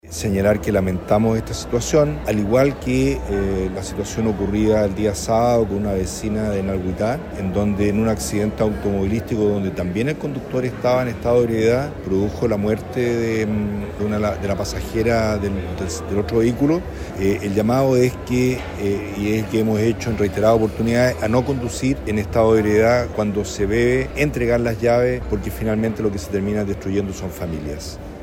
Ante ello, el delegado de Chiloé, Marcelo Malagueño, lamentó este nuevo deceso en las rutas del archipiélago.